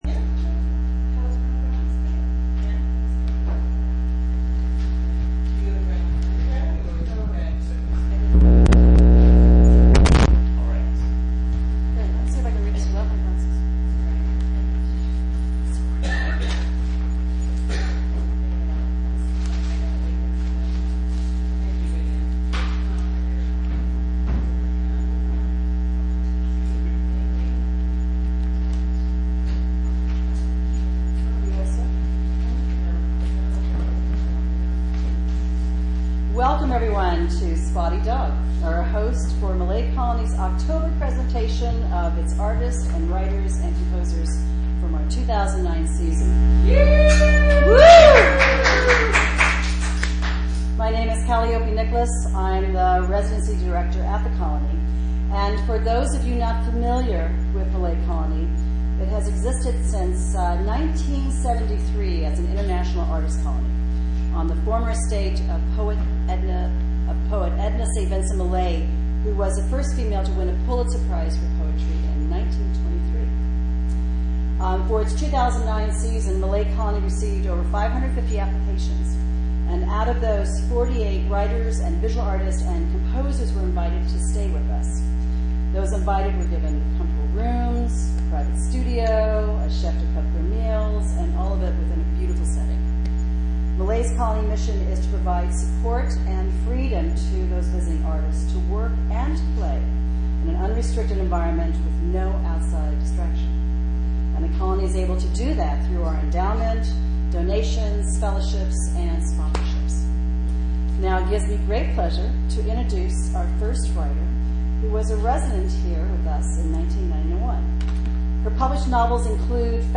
Columbia County ArtsWalk 2009: Oct 09, 2009 - Oct 18, 2009